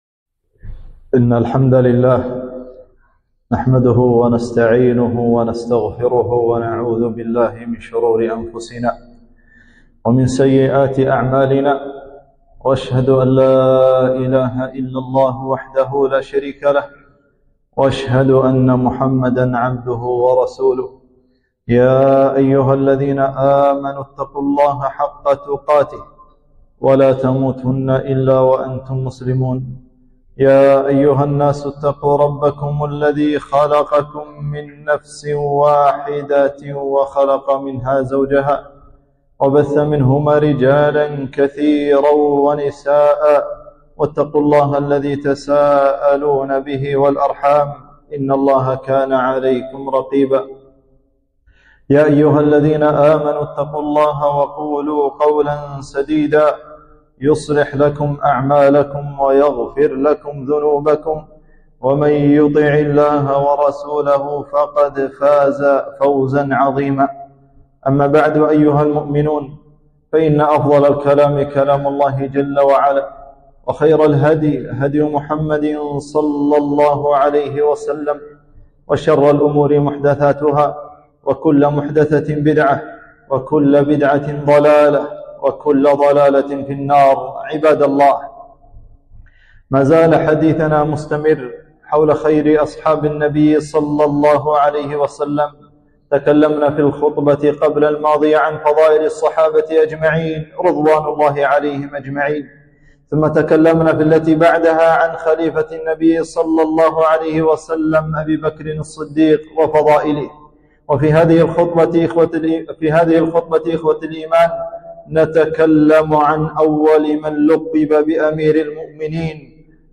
خطبة - فضائل عمر الفاروق